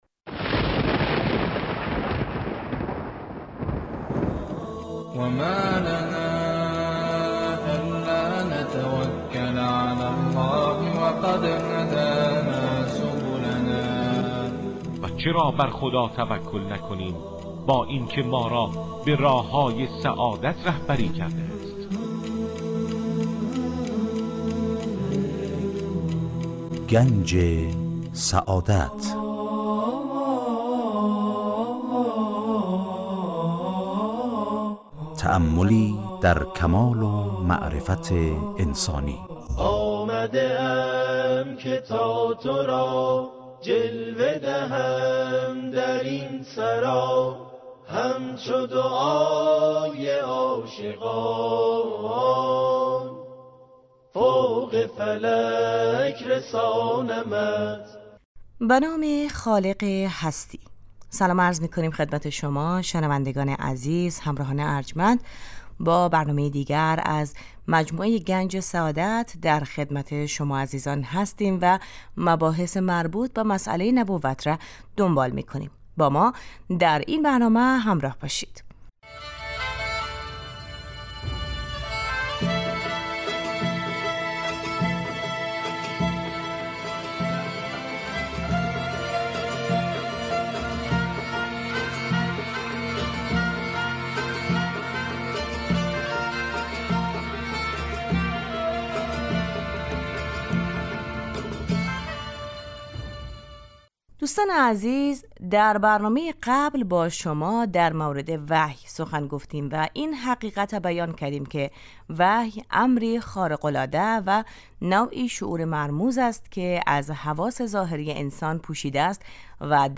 در این برنامه سعی می کنیم موضوعاتی همچون ؛ آفرینش ، یکتاپرستی و آثار و فواید آن، همچنین فلسفه و اهداف ظهور پیامبران را از منظر اسلام مورد بررسی قرار می دهیم. موضوعاتی نظیر عدل خداوند، معاد و امامت از دیگر مباحثی است که در این مجموعه به آنها پرداخته می شود این برنامه هر روز به جزء جمعه ها حوالی ساعت 12:35 از رادیودری پخش می شود.